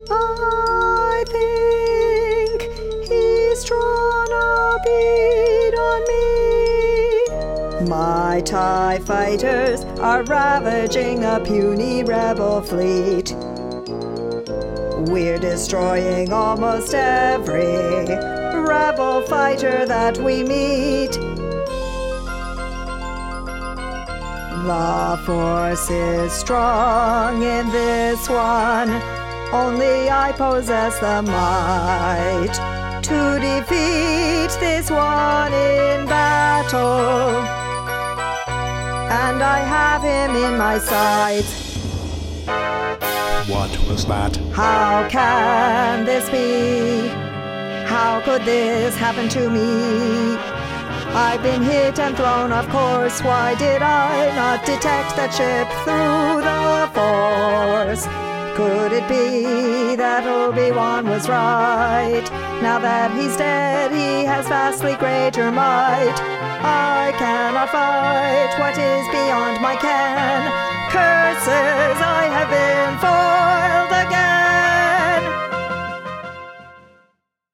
Hear the passage with Vader singing